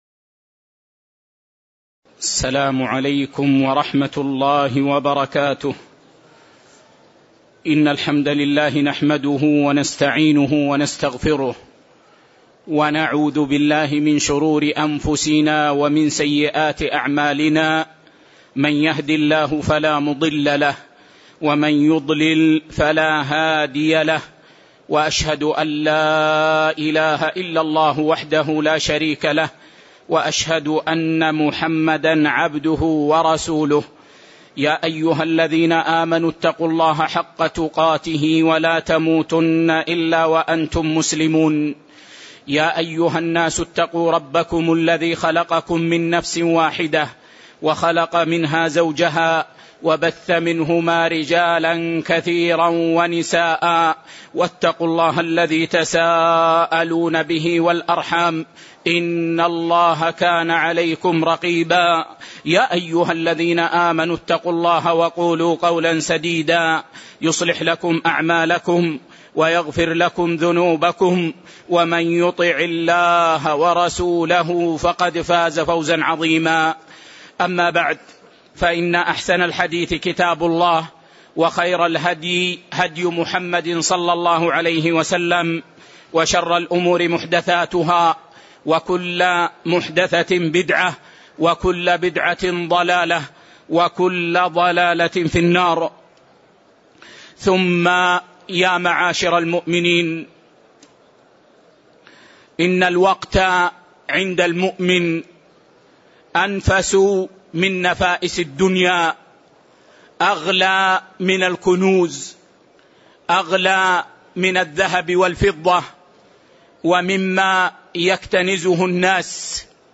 تاريخ النشر ٤ ربيع الأول ١٤٣٨ هـ المكان: المسجد النبوي الشيخ